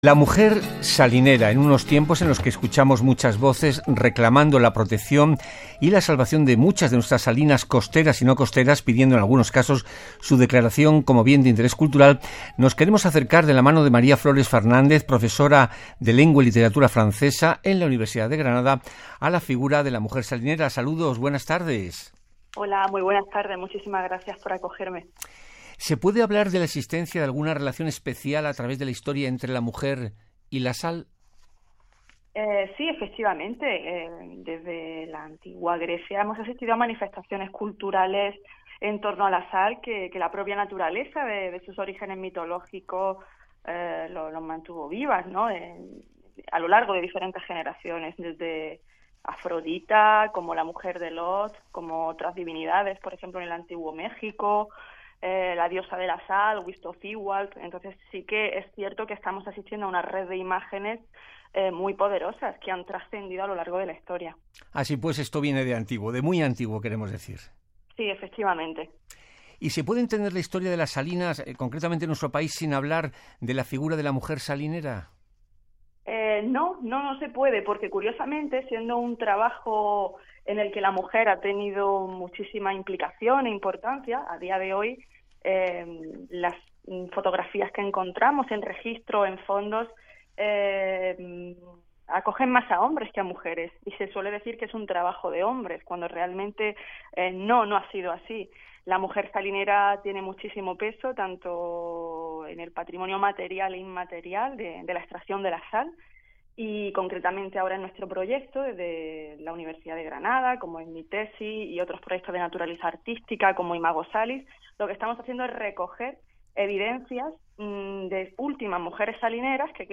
Radio Exterior de España entrevista